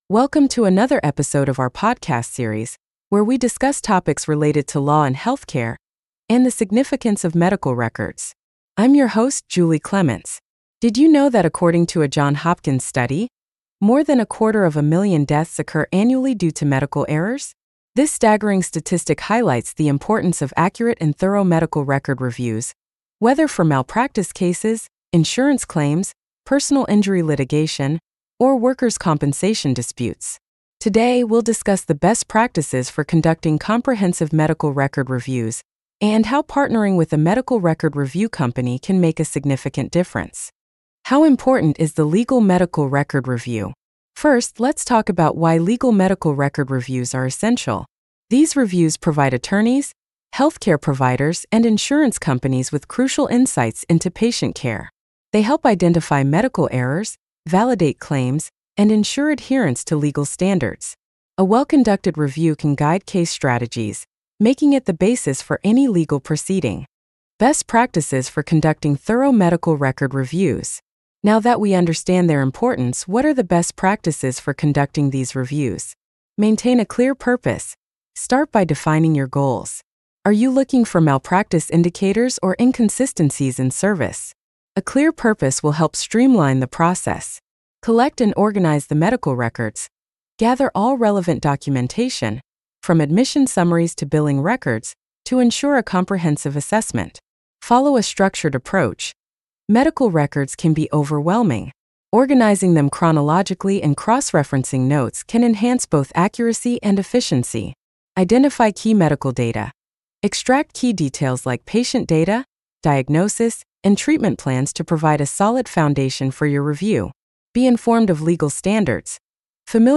Disclaimer: This podcast features human-written content, narrated using AI-generated voice.